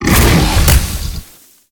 Sfx_creature_snowstalker_flinch_land_02.ogg